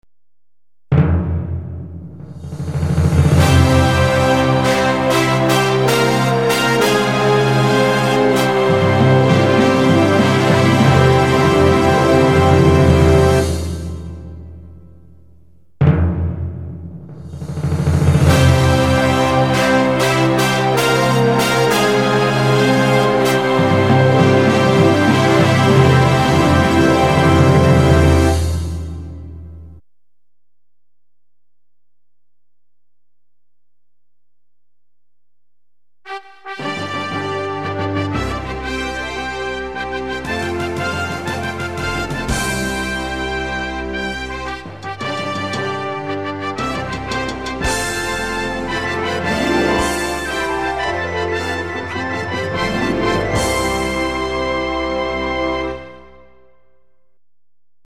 Торжественное награждение